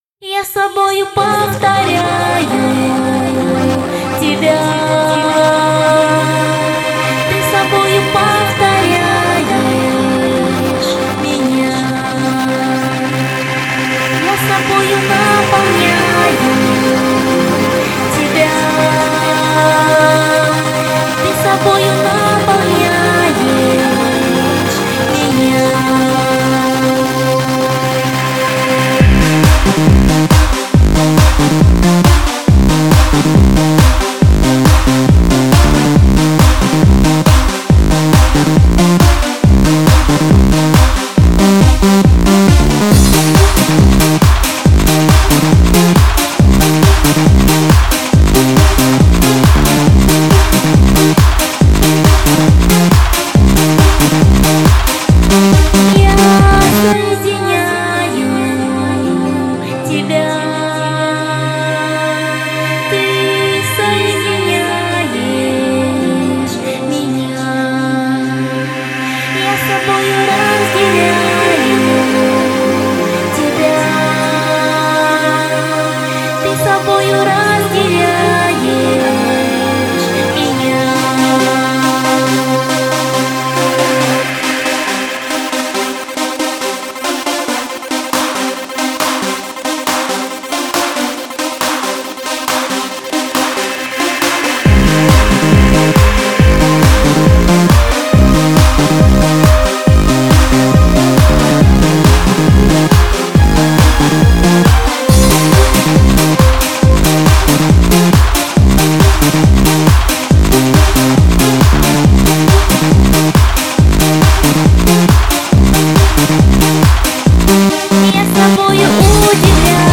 • Жанр: Поп